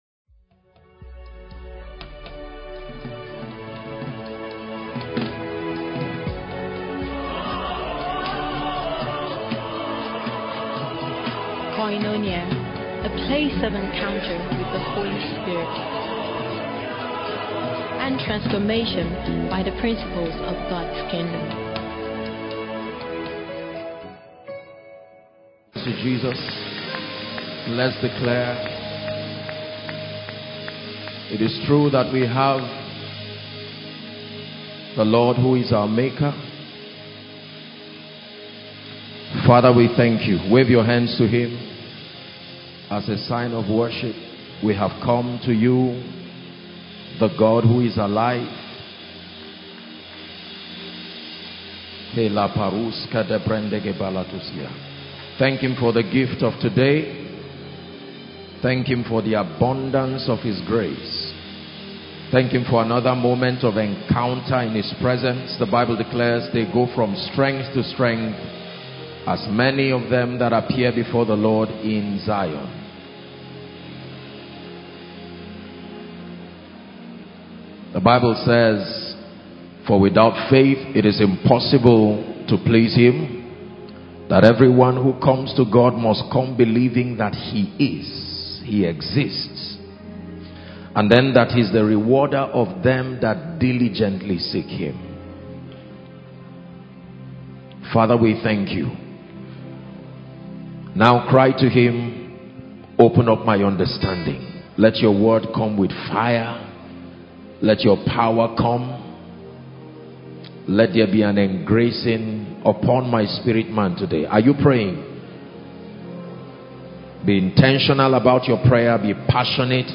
This Grace Called Favour | Sermons - Koinonia Global